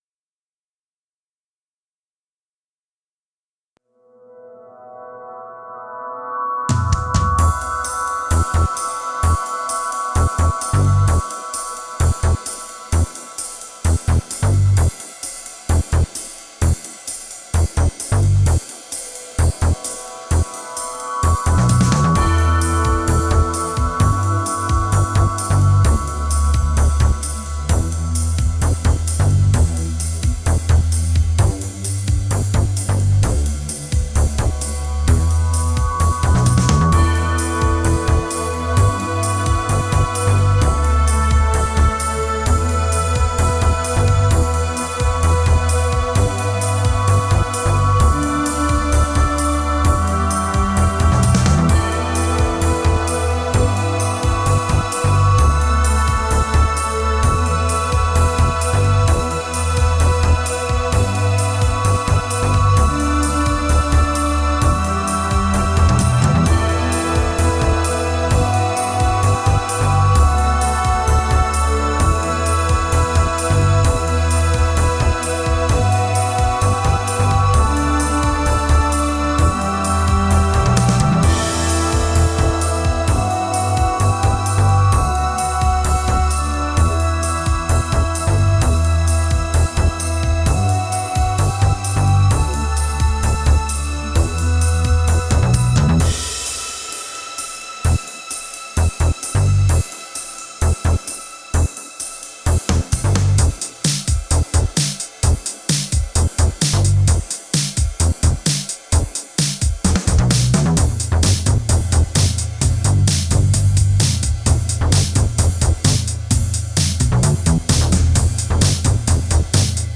Voici donc la version avec gratte (pas de solo donc :) ) que j'ai faite en rentrant du boulot :)
bien sympa avec cette gratte. ;)